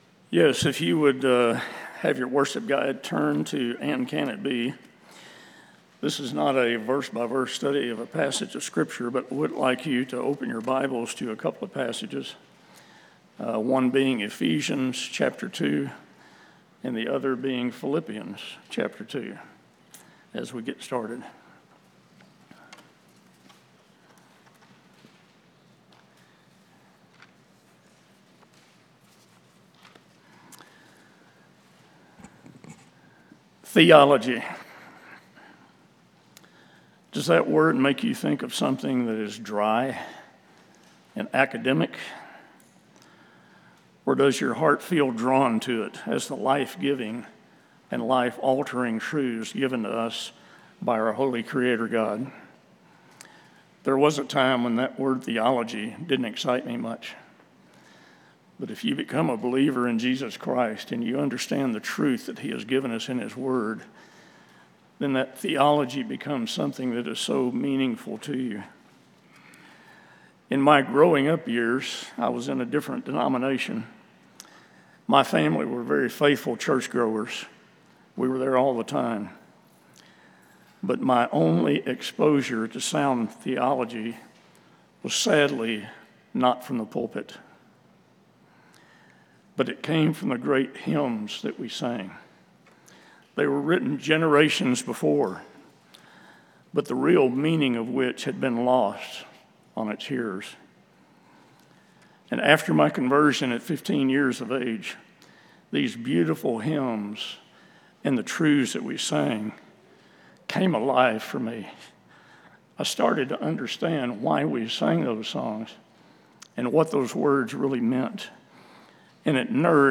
CCBC Sermons Theology and Music: How the Songs We Sing Shepherd Our Souls (And Can It Be?)